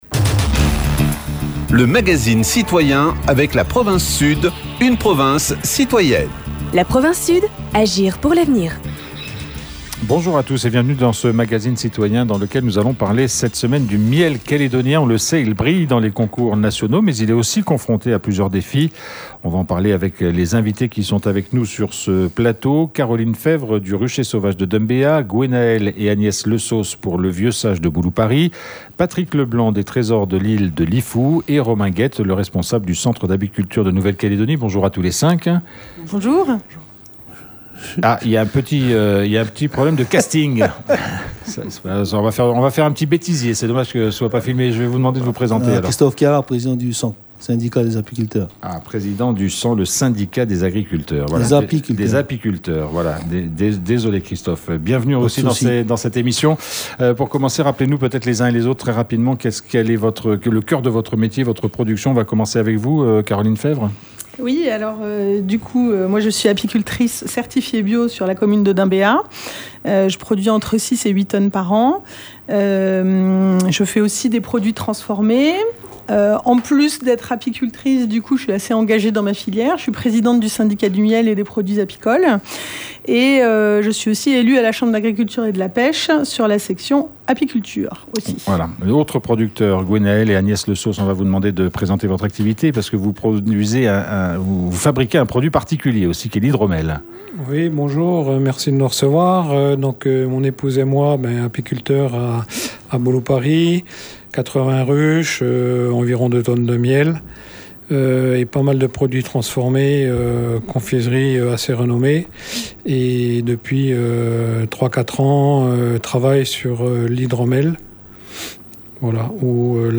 Plusieurs apiculteurs ont remporté des médailles lors d’un Concours des miels de France 2026 au début du mois. Retour sur ce concours et sur la qualité de la production calédonienne avec plusieurs professionnels primés.